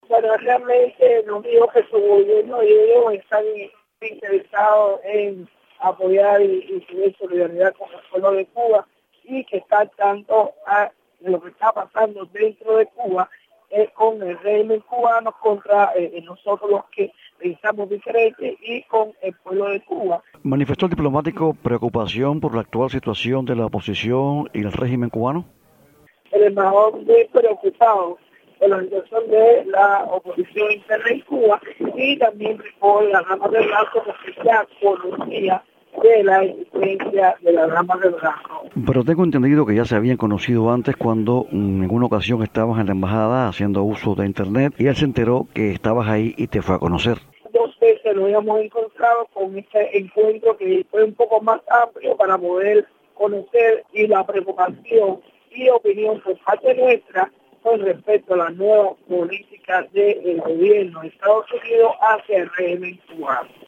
Declaraciones de Berta Soler, líder de las Damas de Blanco